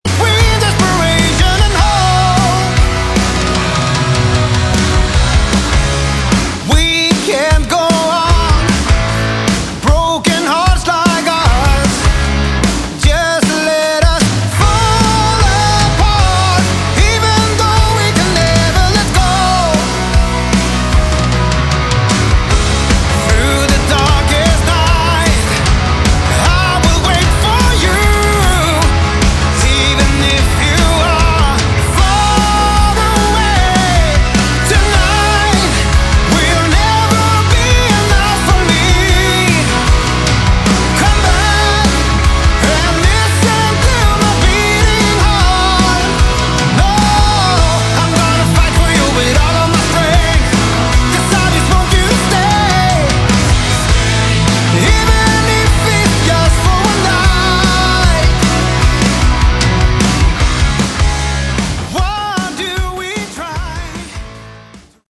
Category: Melodic Rock
vocals
guitar
keyboards
bass guitar
drums